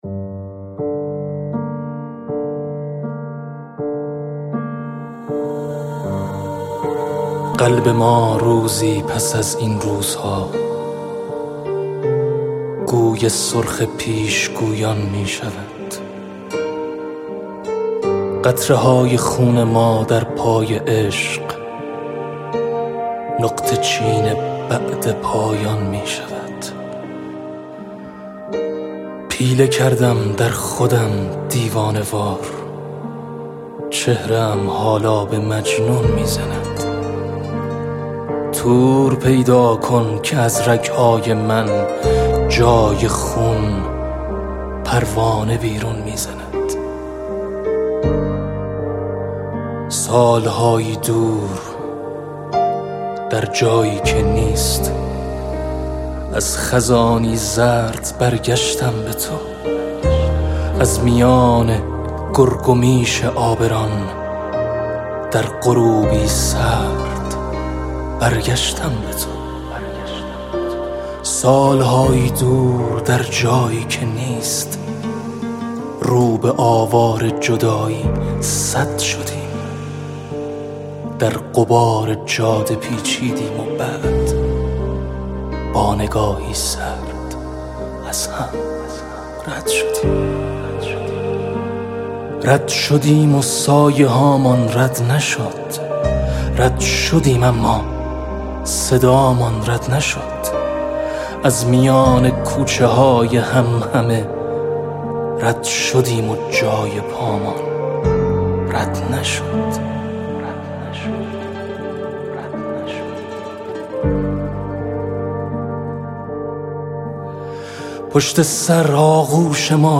اطلاعات دکلمه